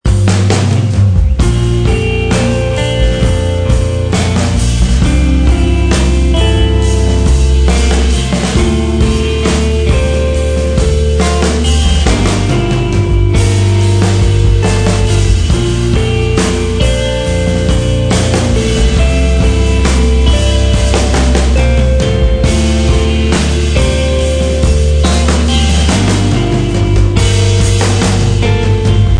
Post rock ed Ambient.